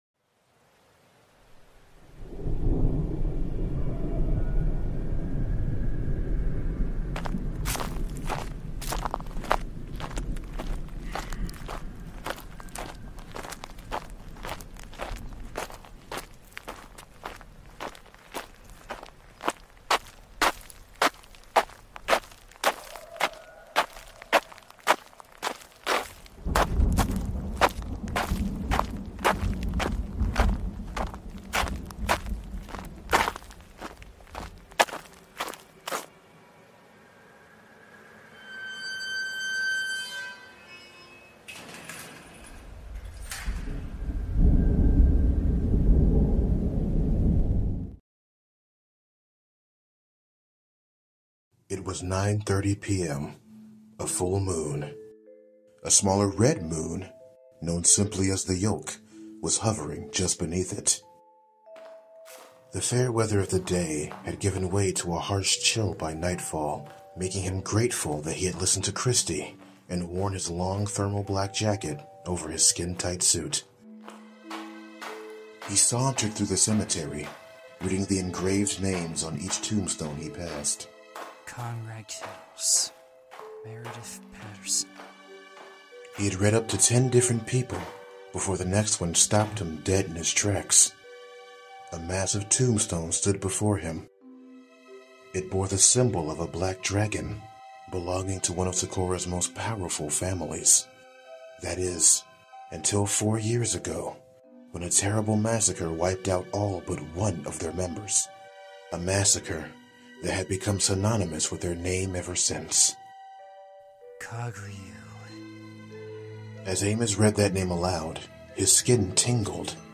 Academy Showdown Gaiden: Paradise on Terra | Audio Drama